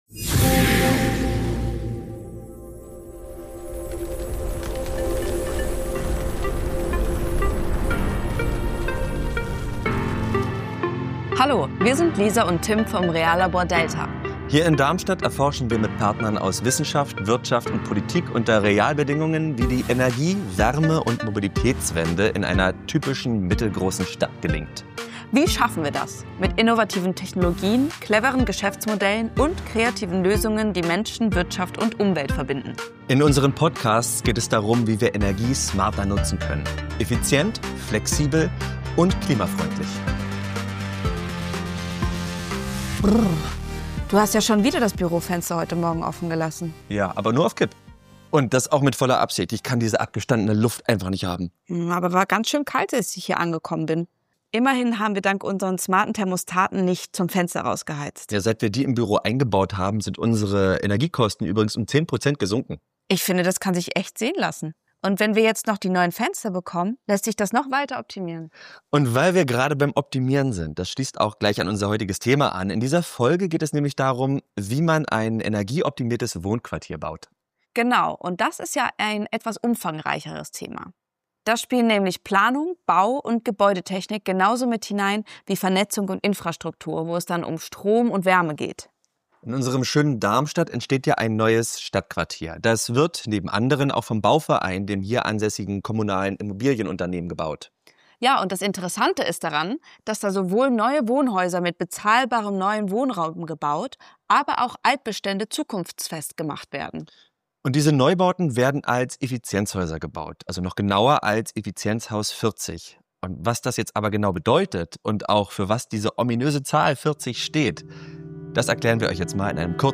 Interviewpartnern